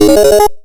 Soundspack 05 (8bit SFX 01-... / SFX / PowUp / PowUp_02.mp3
PowUp_02.mp3